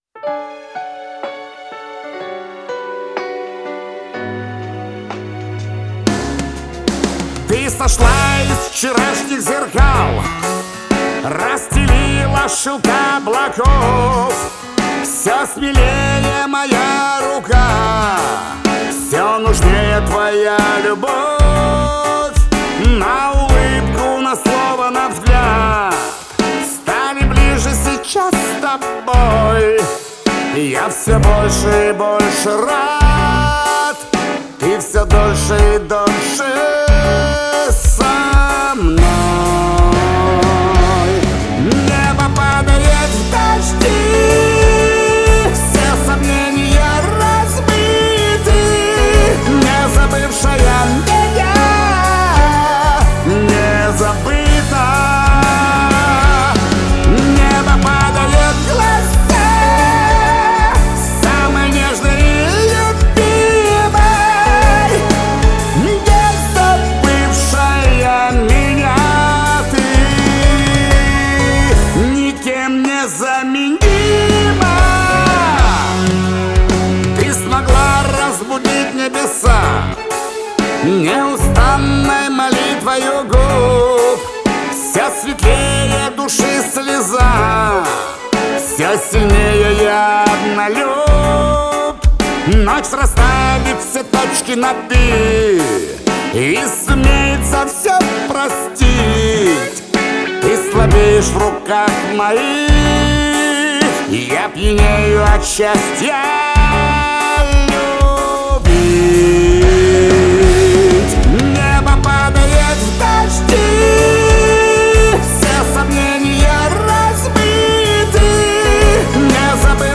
Настроение композиции – меланхоличное, но с нотками надежды.